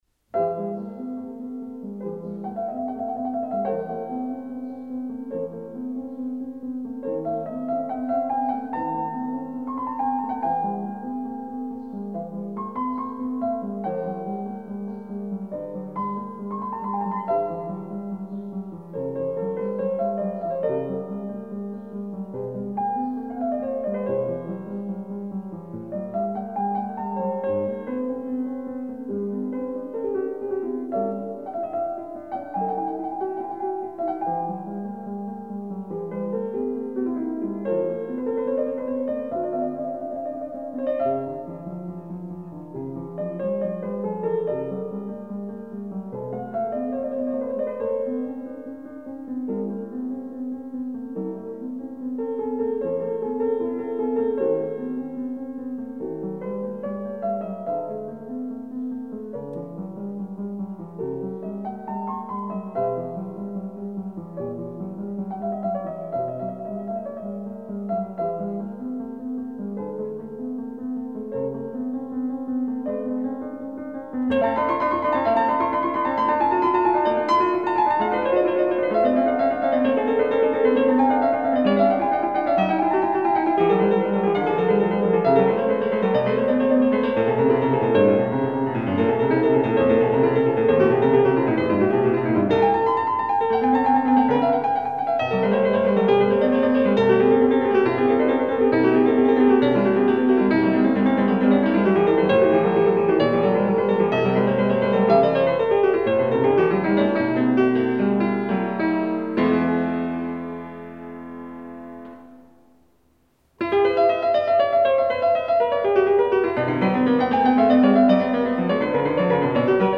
Прелюдия и фуга № 10 e-moll
06 - Бах И.С. -  (Рихтер С.) - ХТК  I том  Прелюдия и фуга №10 e-moll.mp3